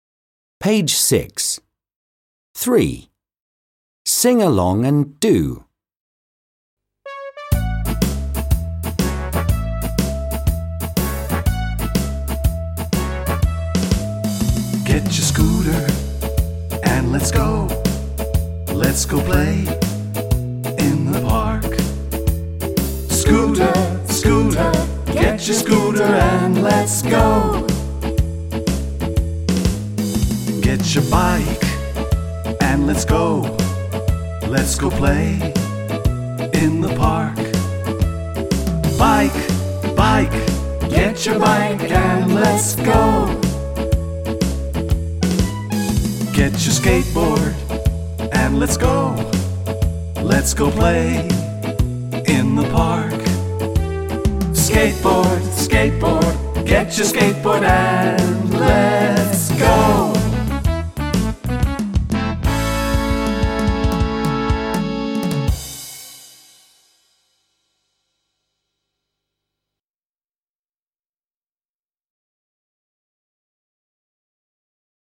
1/4 Chants and songs